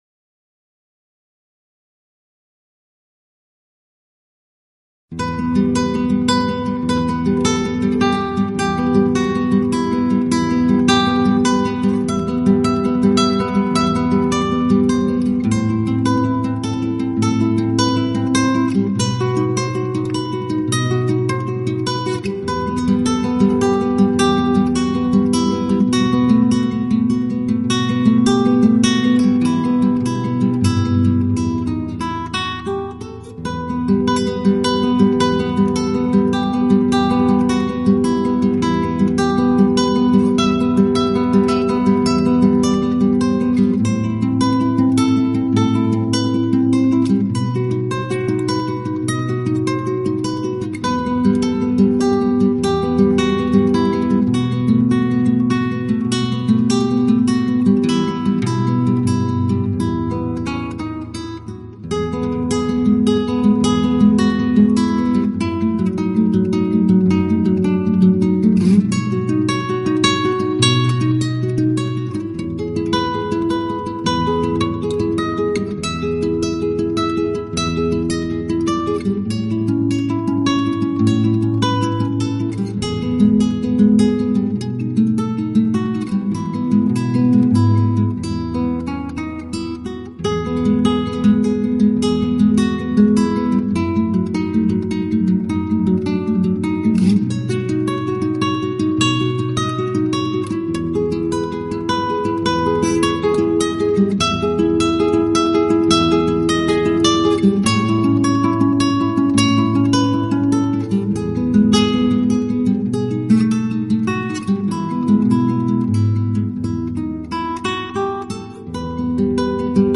音又极其细致、干净、层次分明，配器简洁明了，是近年市场上难得的一套西